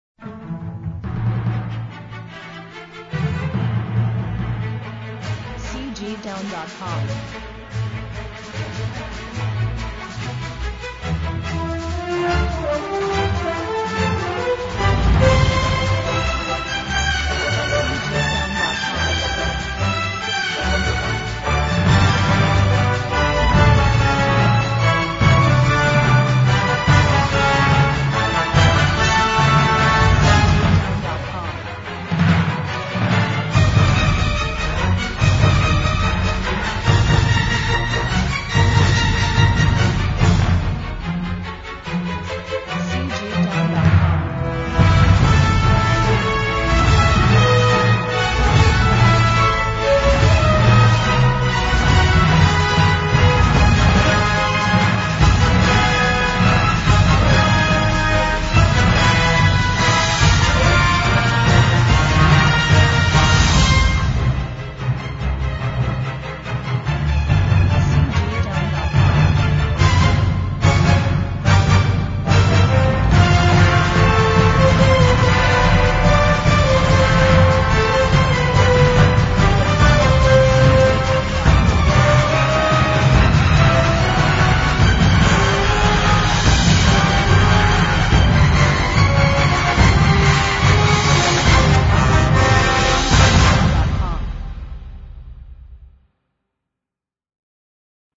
机动表演